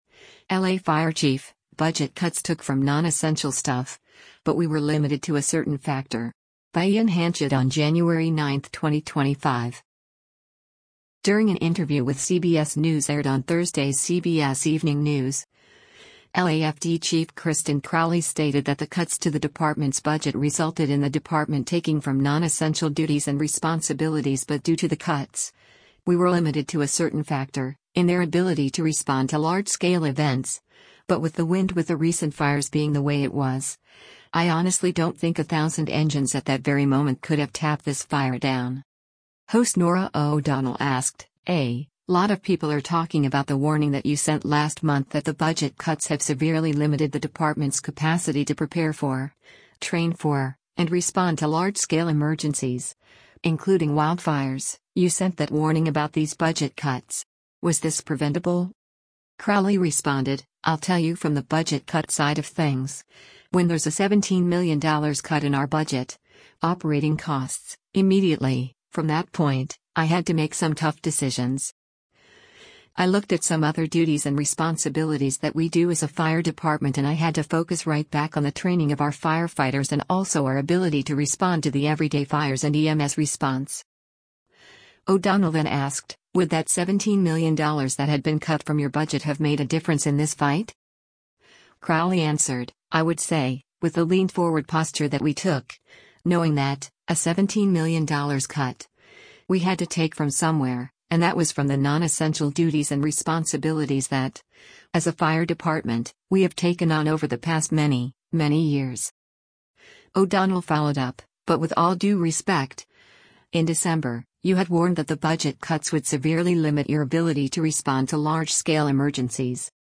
During an interview with CBS News aired on Thursday’s “CBS Evening News,” LAFD Chief Kristin Crowley stated that the cuts to the department’s budget resulted in the department taking from “nonessential duties and responsibilities” but due to the cuts, “we were limited to a certain factor,” in their ability to respond to large-scale events, but with the wind with the recent fires being the way it was, “I honestly don’t think a thousand engines at that very moment could have tapped this fire down.”